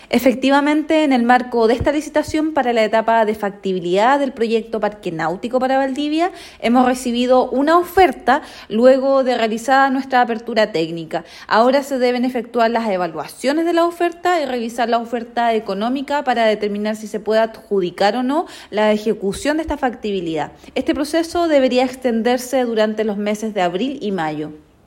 Ante ello, la seremi de Obras Públicas, Nuvia Peralta, afirmó que ahora se vienen los pasos previos a la adjudicación, lo que se debería efectuar entre abril y mayo.